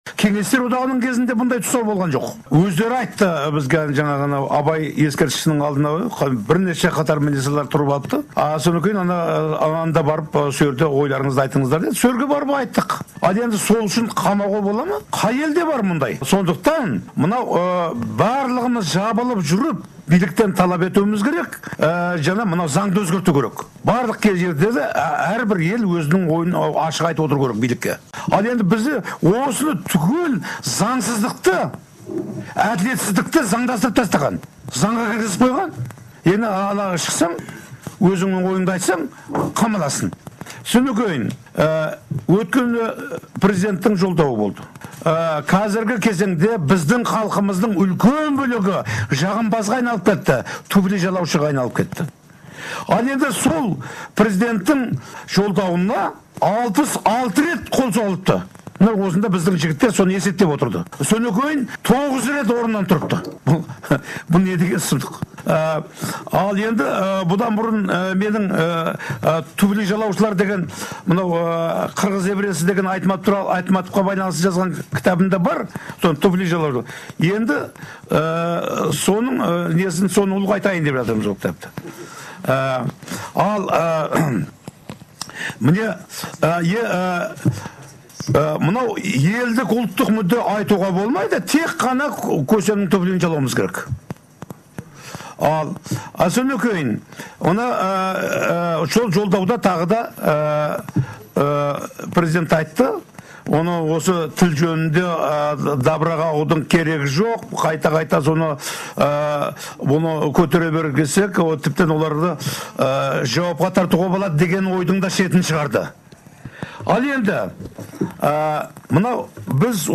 Мұхтар Шахановтың сөзі